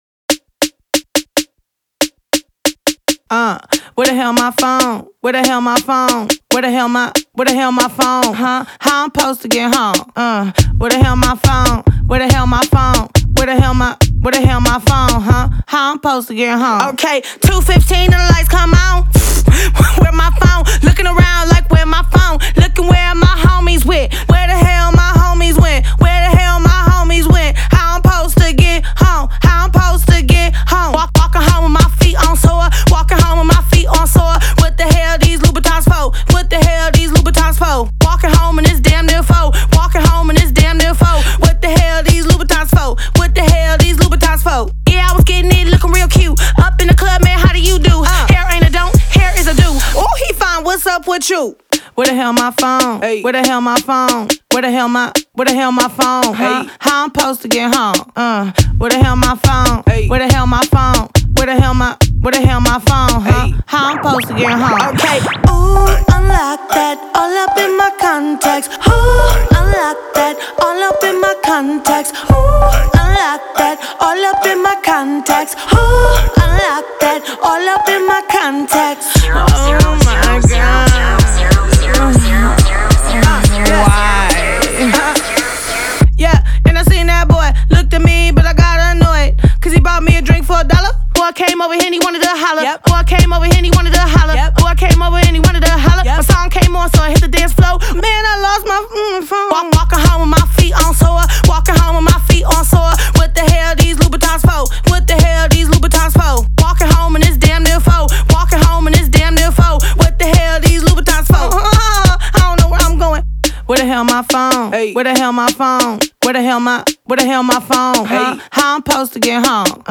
장르: Hip Hop, Funk / Soul
스타일: Contemporary R&B, Pop Rap